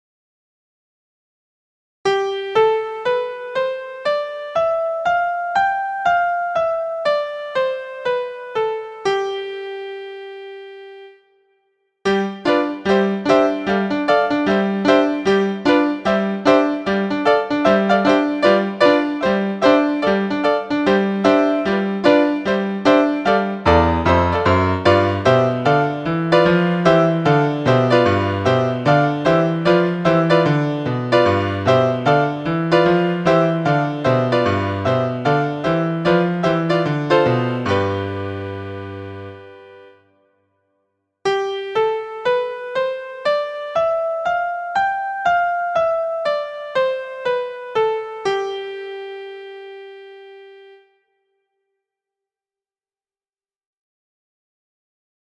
MIXOLYDIAN
Mixolydian.mp3